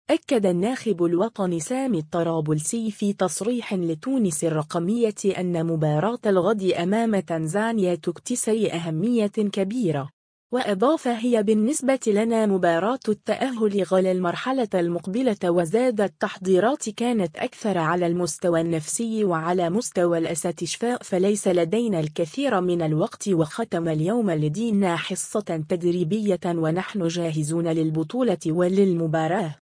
أكّد الناخب الوطني سامي الطرابلسي في تصريح لتونس الرقمية أنّ مباراة الغد أمام تنزانيا تكتسي أهمية كبيرة.